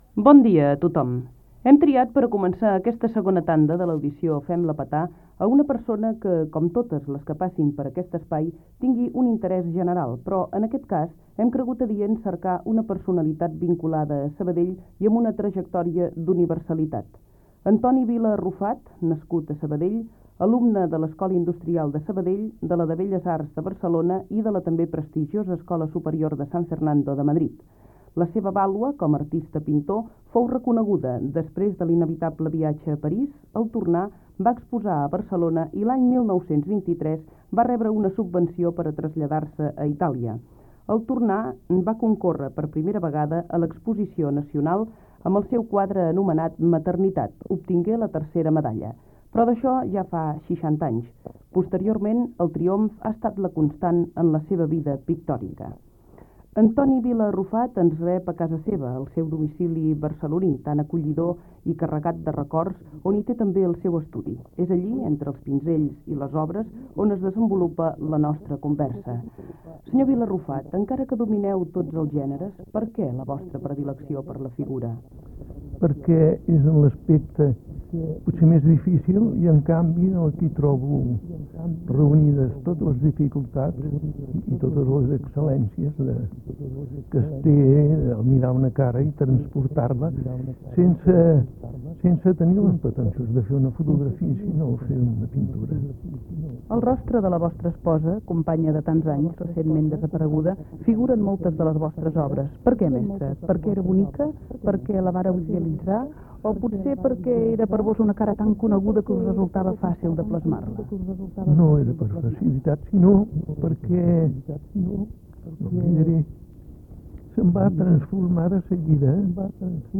Presentació i entrevista al pintor Antoni Vila Arrufat